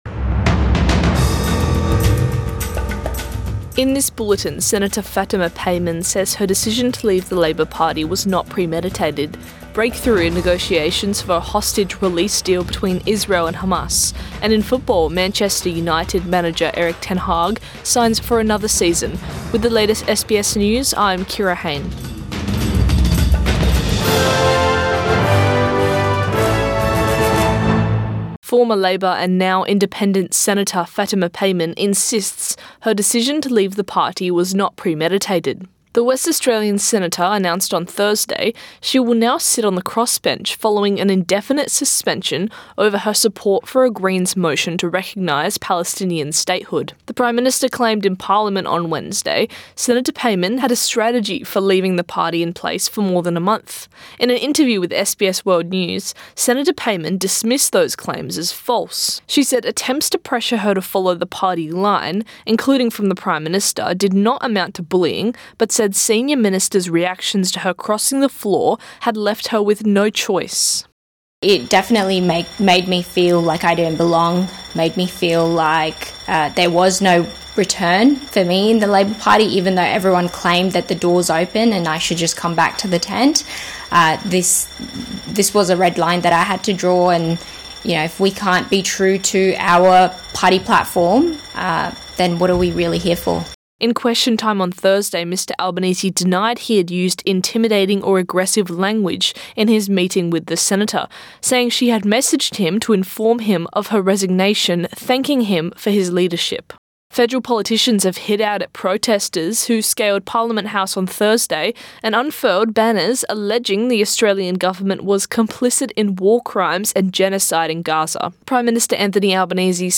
Morning News Bulletin 5 July 2024